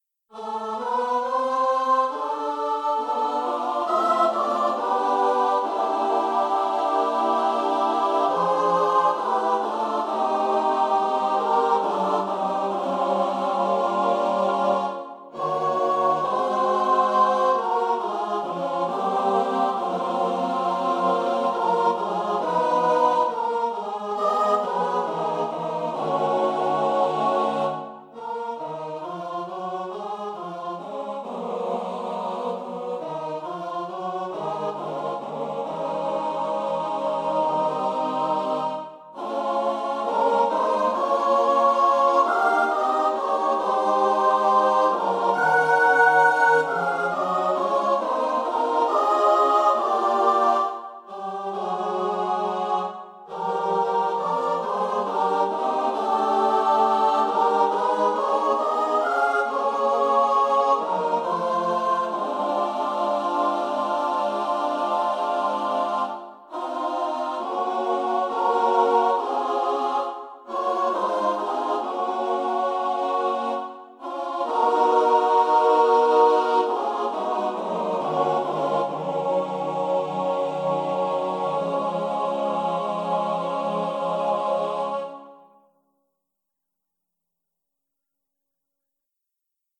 for SATB a cappella